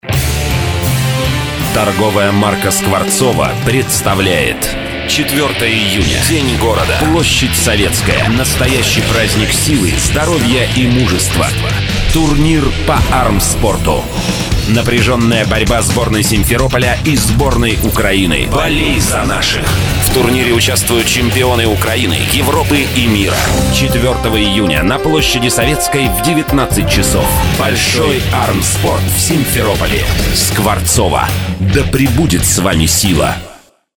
Портфолио | Радио реклама | Аудио реклама | Радио ролик | Аудио ролик